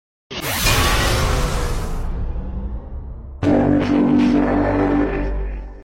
evo pekka Meme Sound Effect
Category: Games Soundboard